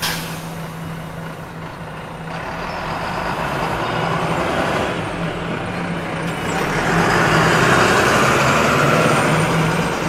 bus.ogg